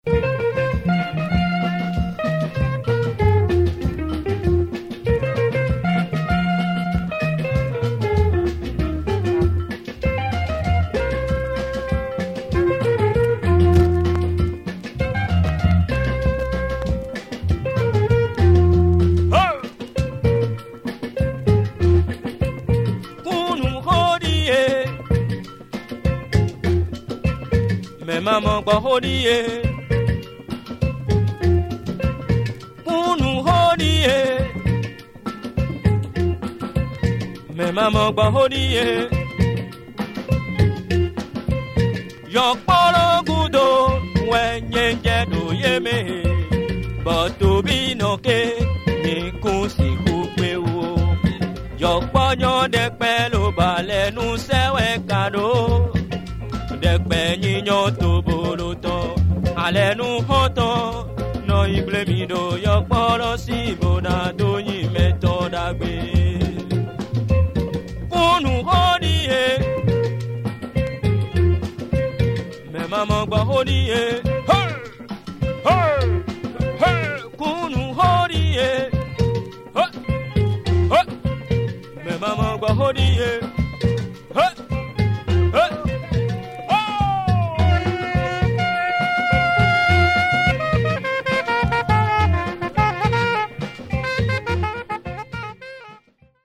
afro groove